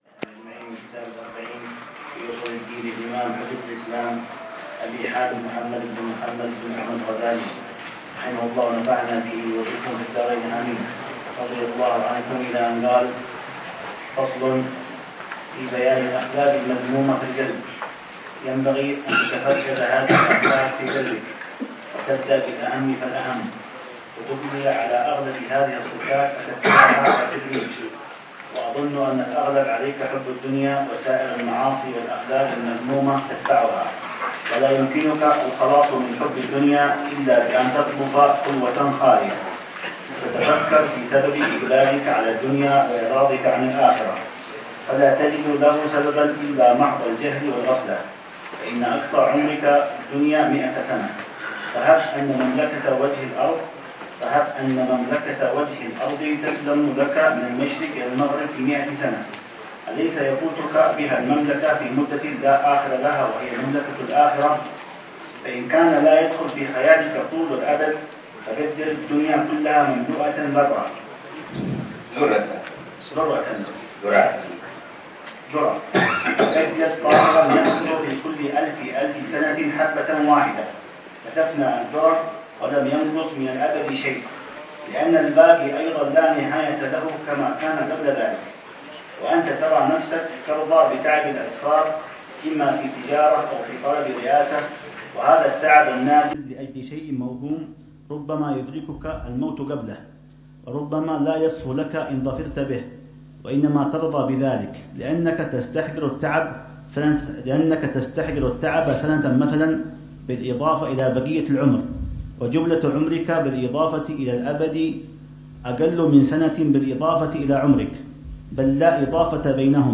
الدرس ال31 في شرح الأربعين في أصول الدين: خاتمة في مجامع الأخلاق والغرور منها